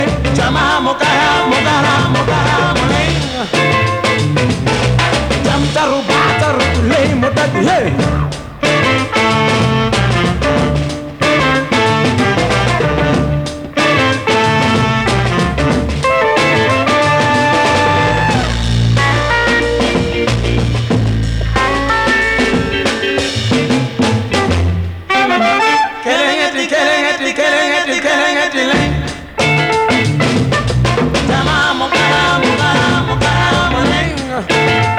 Жанр: Поп музыка
Latin, African, Afro-Pop